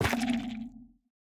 Minecraft Version Minecraft Version latest Latest Release | Latest Snapshot latest / assets / minecraft / sounds / block / sculk_sensor / place1.ogg Compare With Compare With Latest Release | Latest Snapshot